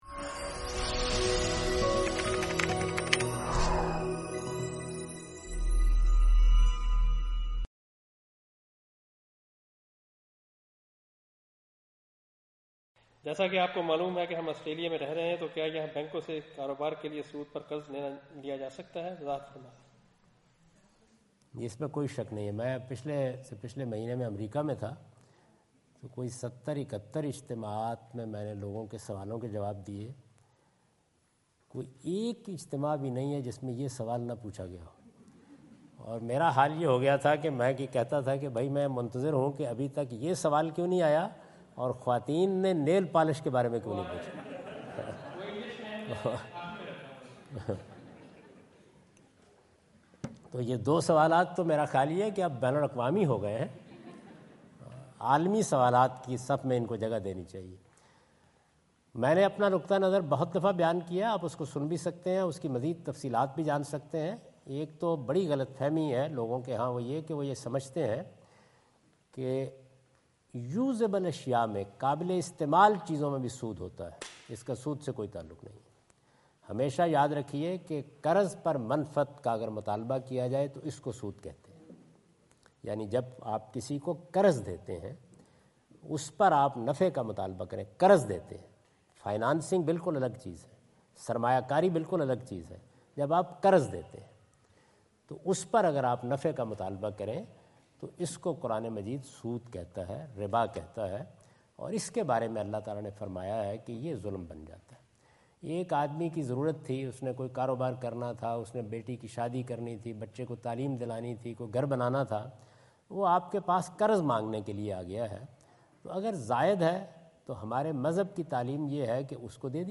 Javed Ahmad Ghamidi answer the question about "How Is Interest Different from Mortgage" during his visit in Canberra Australia on 03rd October 2015.
جاوید احمد غامدی اپنے دورہ آسٹریلیا کے دوران کینبرا میں "سود مورٹگیج سے کیسے مختلف ہے؟" سے متعلق ایک سوال کا جواب دے رہے ہیں۔